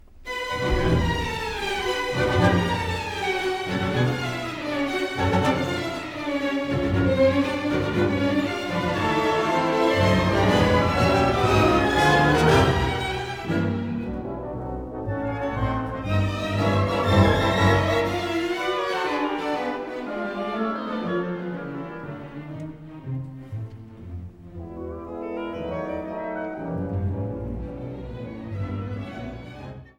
repräsentative Live-Aufnahmen